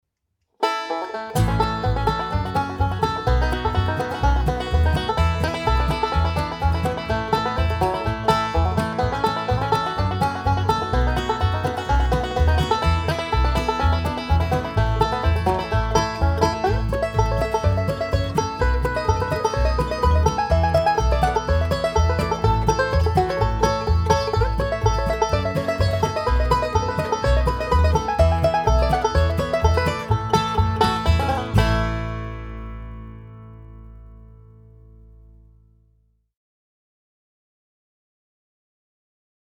DIGITAL SHEET MUSIC - 5-STRING BANJO SOLO
Three-finger "Scruggs style"
learning speed and performing speed